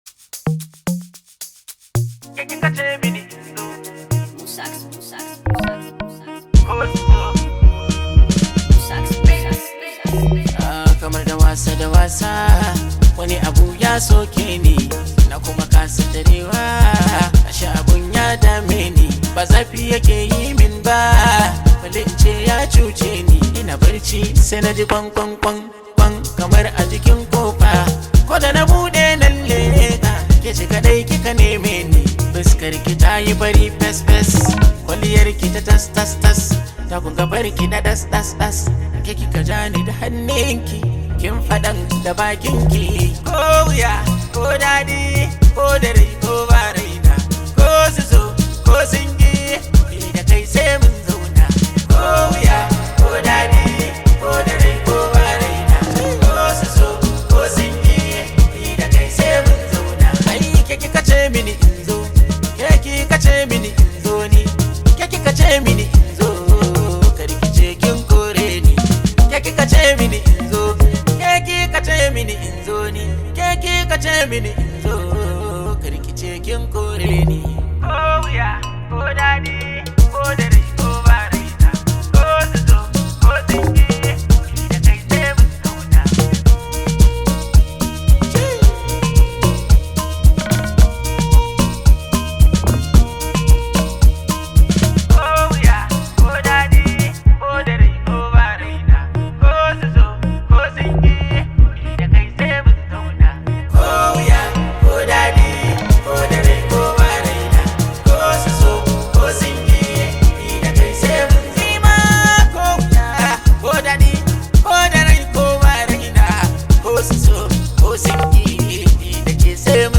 Hausa Music
This high vibe hausa song